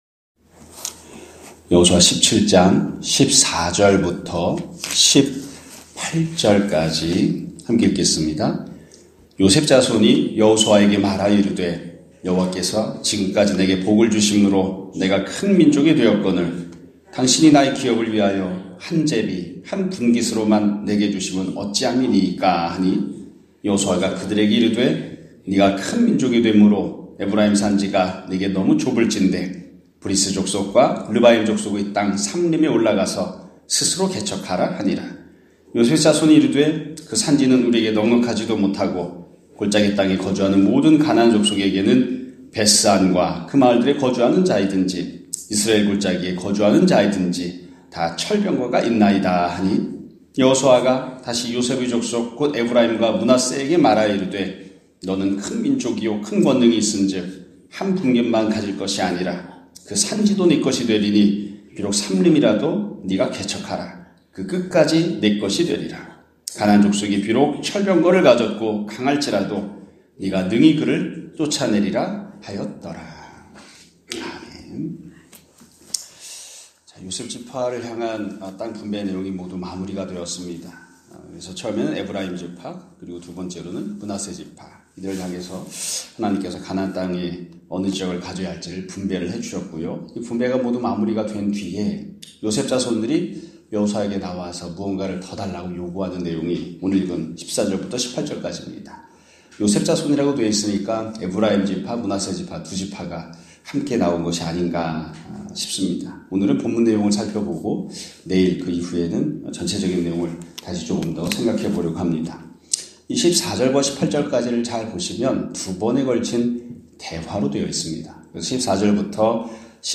2024년 12월 18일(수요일) <아침예배> 설교입니다.